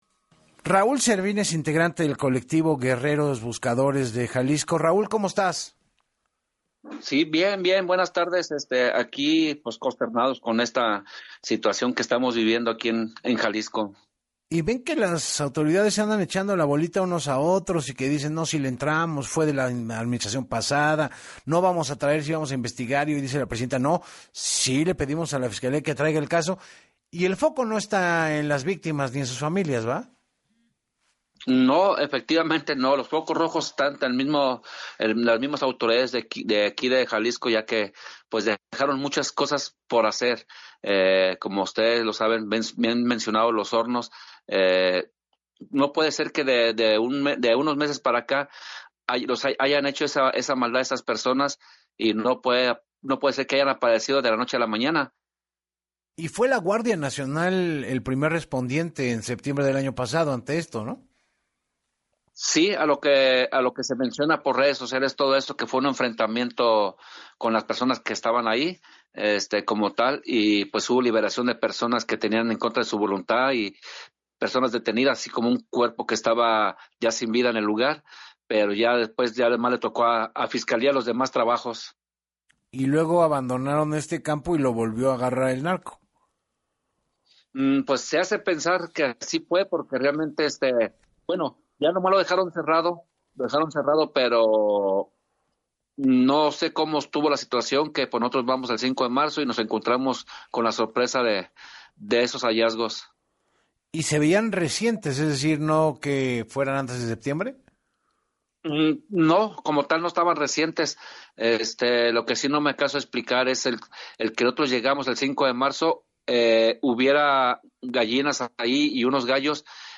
En entrevista para Así Las Cosas con Enrique Hernández Alcázar